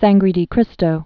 (sănggrē dē krĭstō)